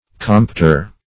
compter - definition of compter - synonyms, pronunciation, spelling from Free Dictionary Search Result for " compter" : The Collaborative International Dictionary of English v.0.48: Compter \Compt"er\, n. A counter.